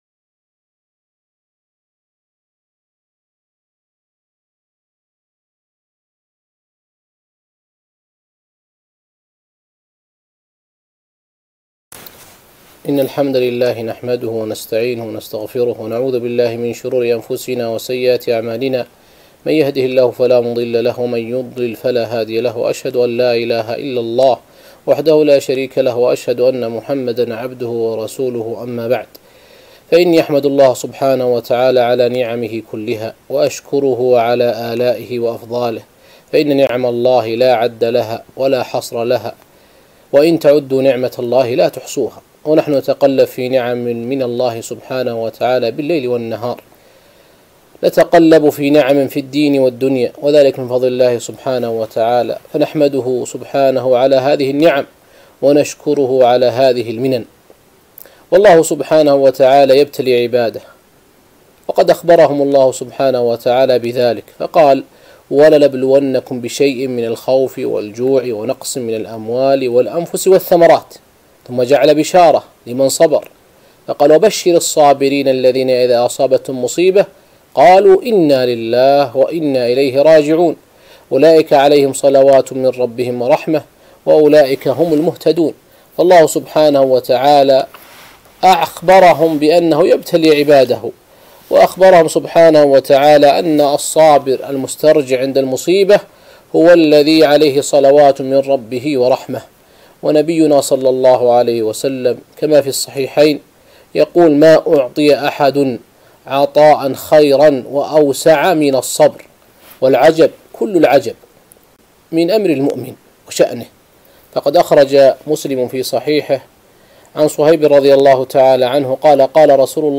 محاضرة - الافتقار إلى الله في الشدة عبر البث المباشر 1441هــ